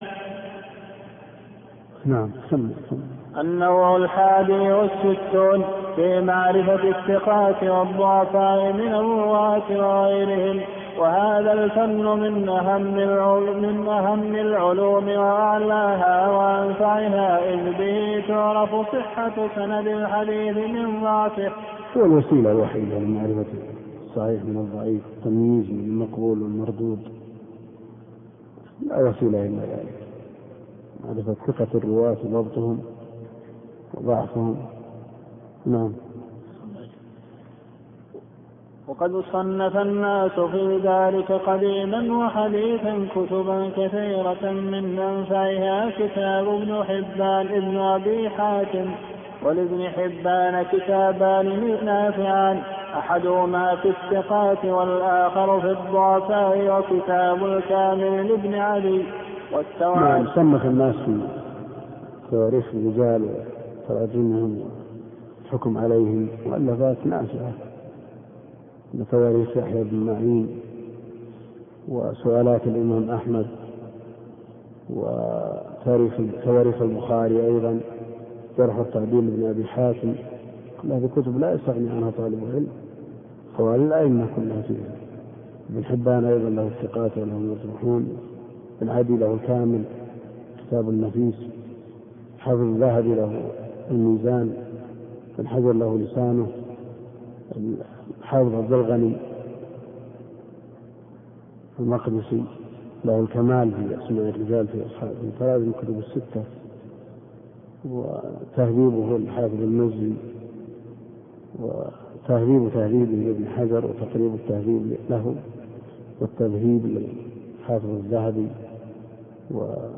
طلب إدراج الشرح الصوتي للشيخ عبد الكريم الخضير (اختصار علوم الحديث) - معهد آفاق التيسير للتعليم عن بعد